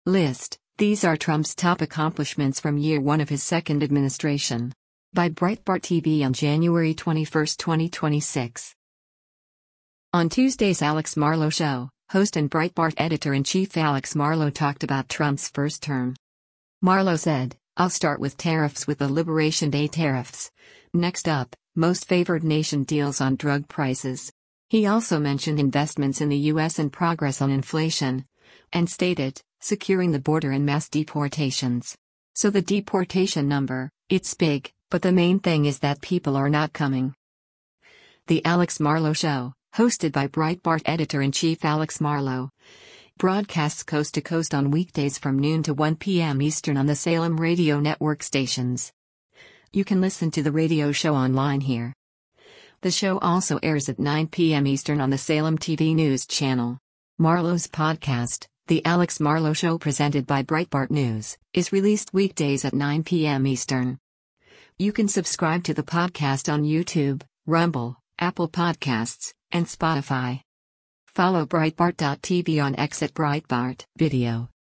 On Tuesday’s “Alex Marlow Show,” host and Breitbart Editor-in-Chief Alex Marlow talked about Trump’s first term.